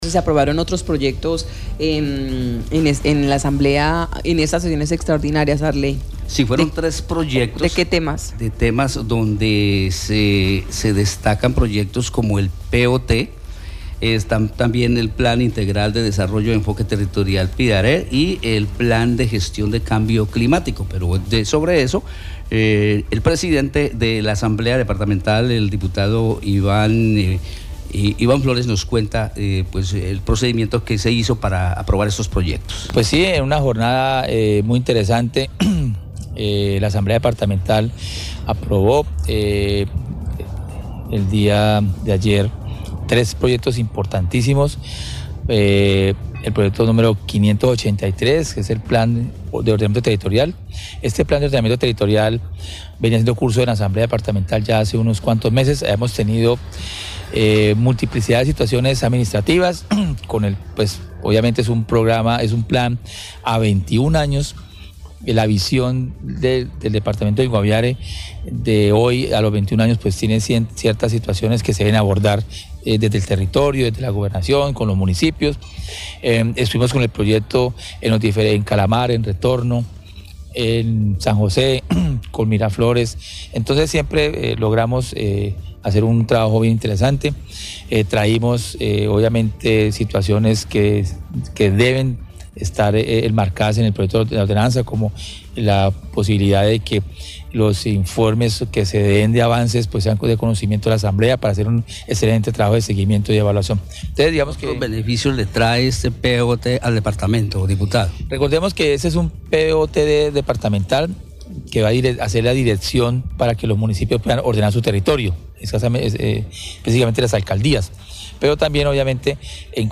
El diputado Iván Flórez, presidente de la Asamblea, explicó la importancia que reviste para el Guaviare y para su población cada uno de estos proyectos que con su aprobación se convierten en ordenanzas del departamento y que serán las herramientas para que los próximos gobernantes puedan avanzar en proyectos medioambientales, agrícolas y pecuarios y el ordenamiento de los territorios en los municipios.